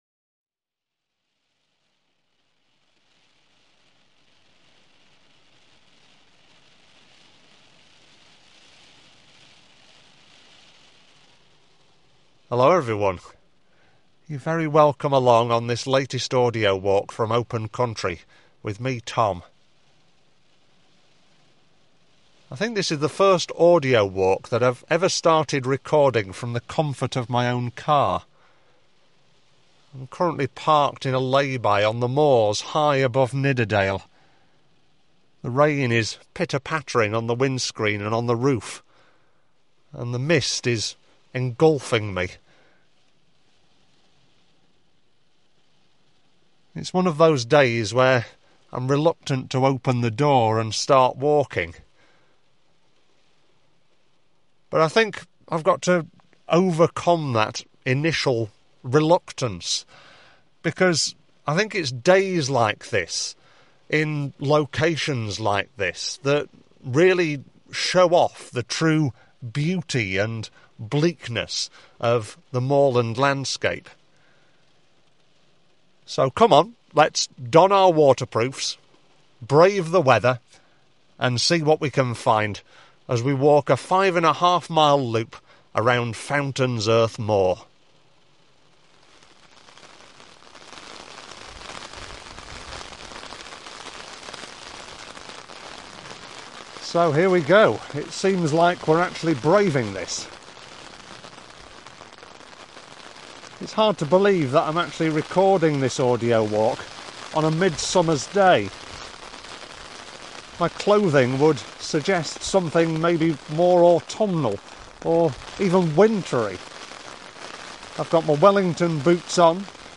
This audio trail accompanies: Fountains Earth Moor Trail
Fountains-Earth-Moor-Audio-Walk.mp3